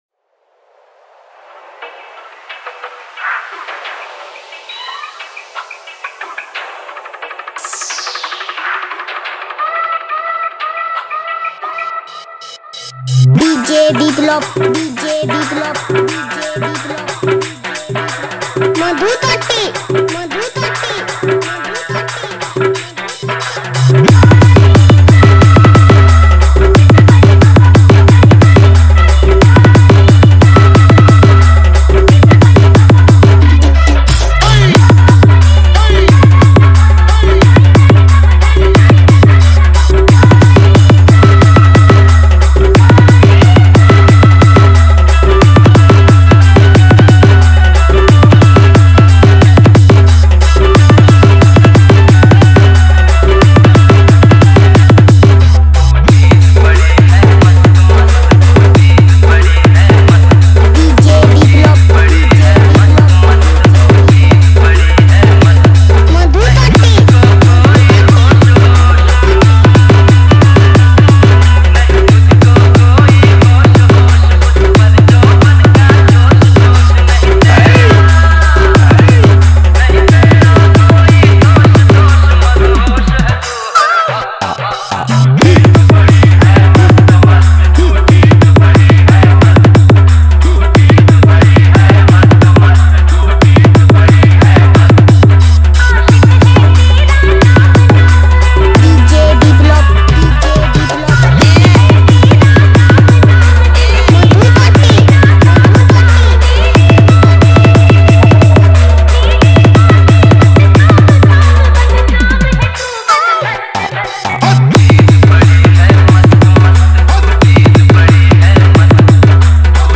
Hindi Dj Song